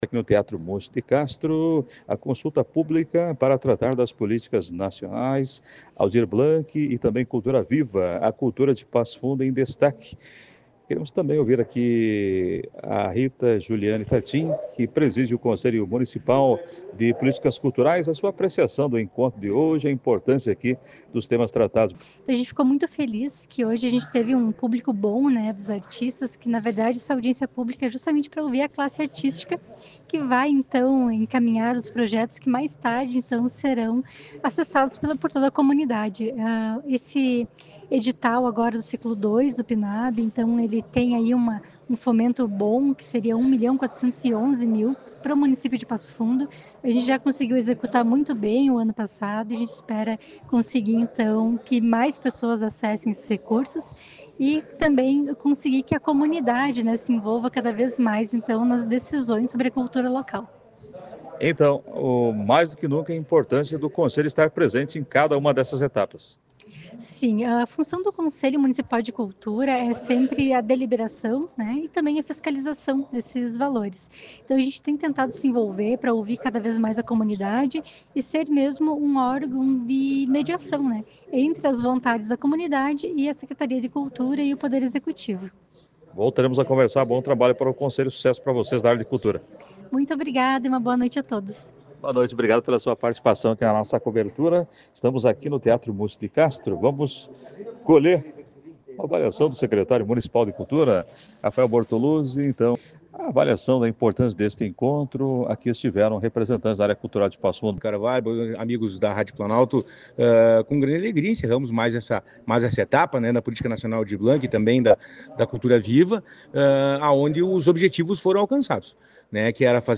Consulta Pública sobre políticas culturais é realizada no Teatro Múcio de Castro